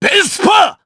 Lusikiel-Vox_Vespa_jp_b.wav